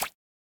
drop_splash_01.wav.mp3